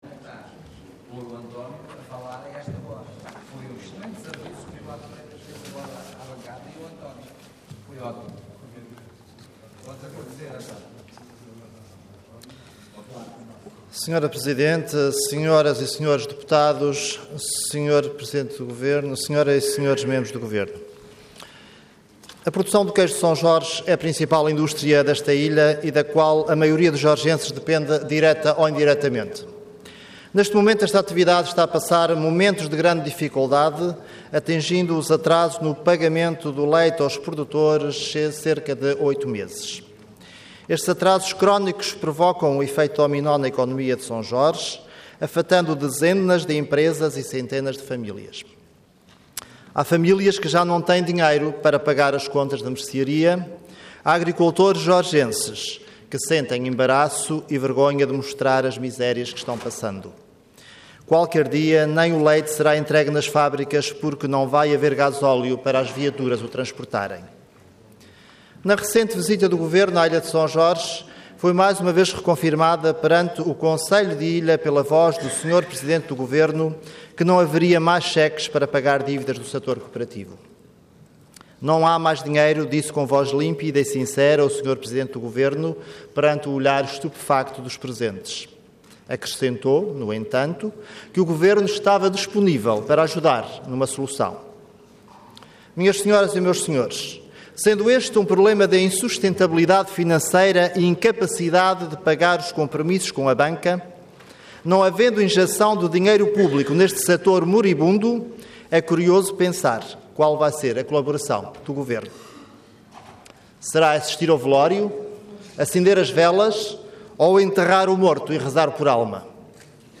Parlamento online - Declaração política.
Website da Assembleia Legislativa da Região Autónoma dos Açores
Intervenção Tratamento de assuntos de interesse político relevante Orador António Pedroso Cargo Deputado Entidade PSD